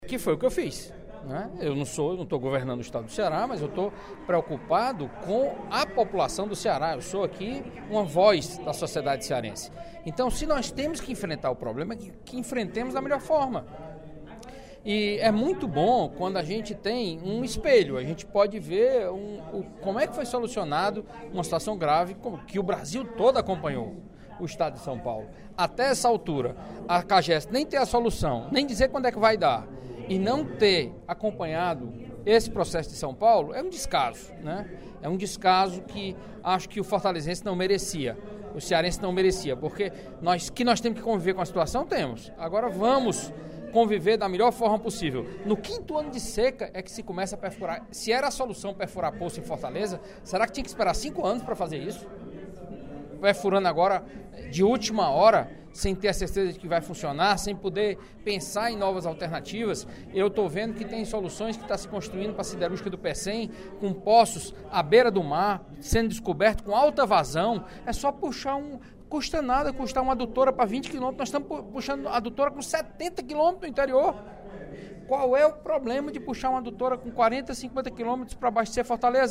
O deputado Carlos Matos (PSDB) apontou, no primeiro expediente da sessão plenária desta quinta-feira (07/07), a falta de planejamento do Governo do Estado, diante do quinto ano consecutivo de crise hídrica.